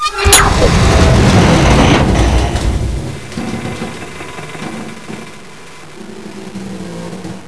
elev_fall.wav